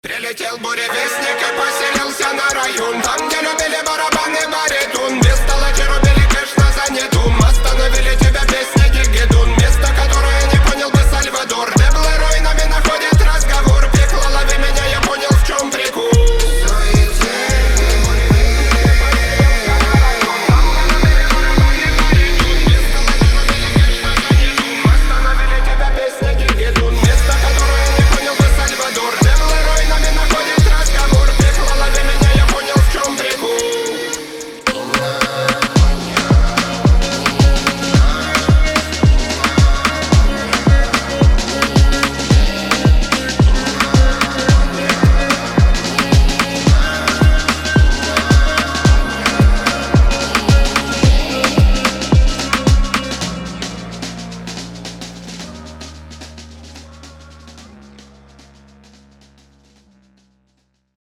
• Качество: 320, Stereo
мужской голос
громкие
мощные
регги